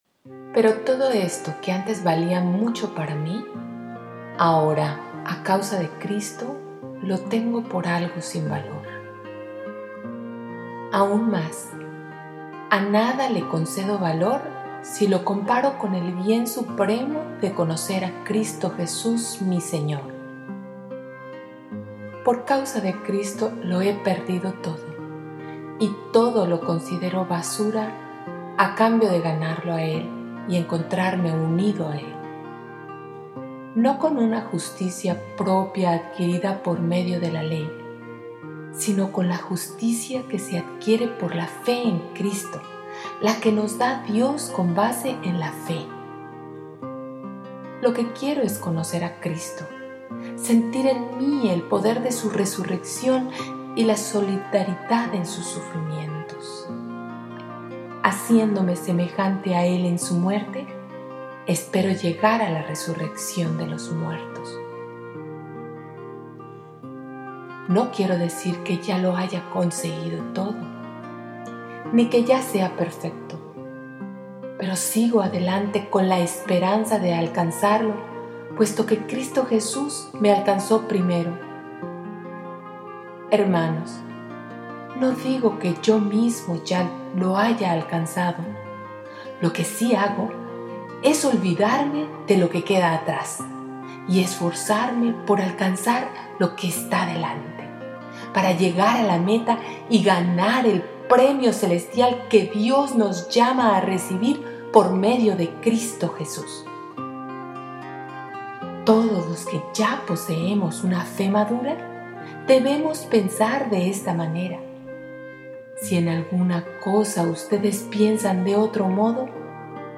LECTURA Y MEDITACION